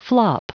Prononciation du mot flop en anglais (fichier audio)
Prononciation du mot : flop